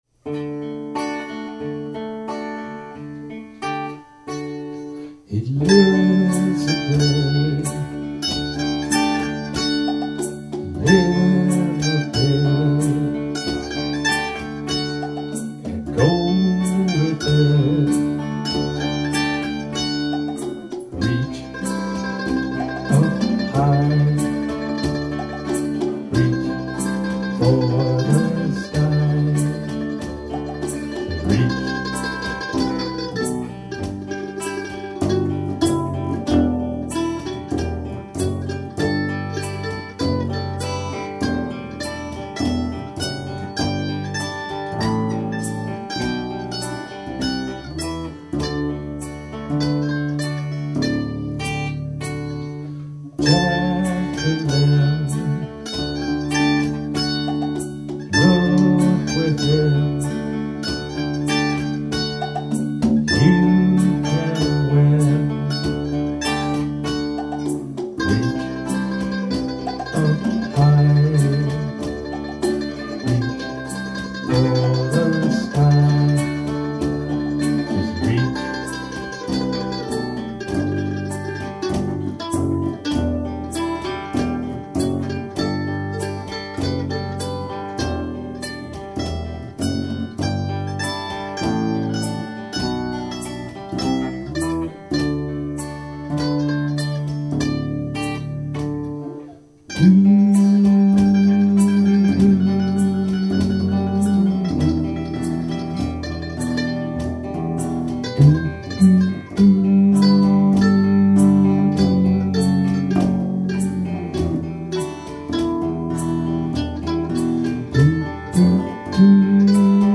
Acoustic and Vocals
Mandolin
Bass
Percussion